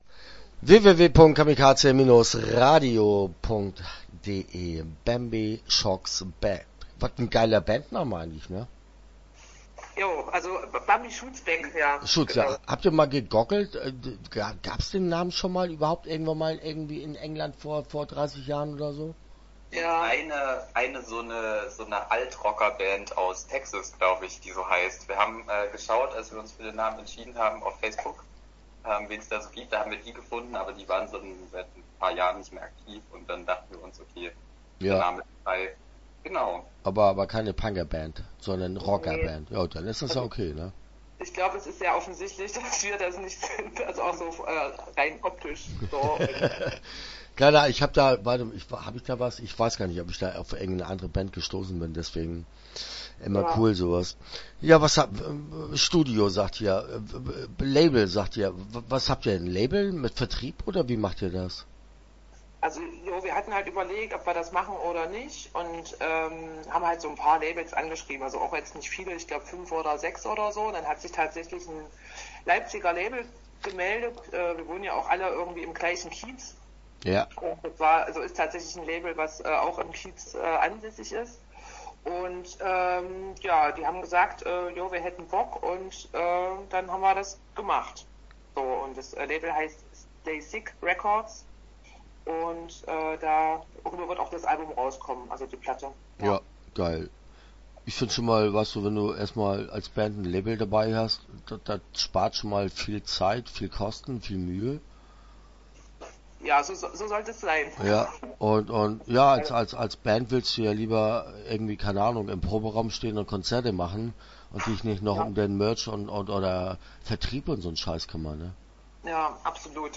Bambi Shoots Back - Interview Teil 1 (10:19)